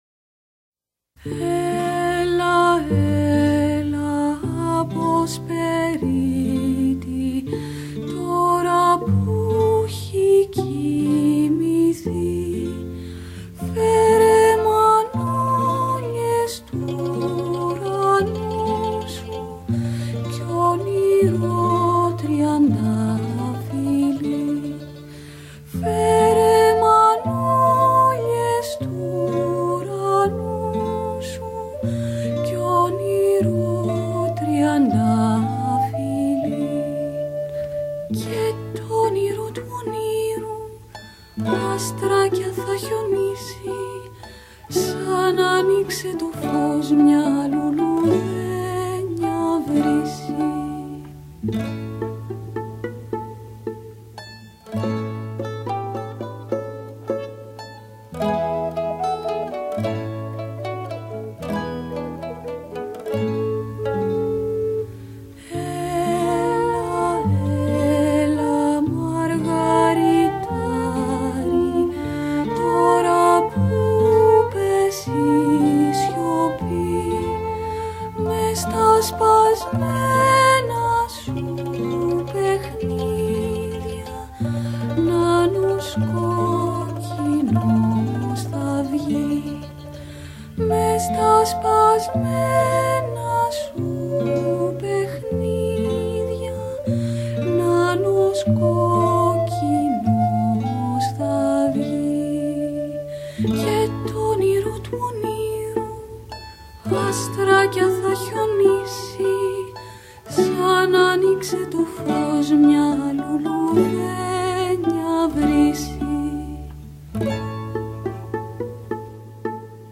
• Degut al seu caràcter dolç, no solen ornamentar-se molt.
• No hi ha una forta projecció de la veu en la majoria de casos i el timbre, tot i que és diferent en cada cas, quasi sempre és càlid i dolç.
01-nanourisma-lullaby.mp3